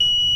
Fx [Beep].wav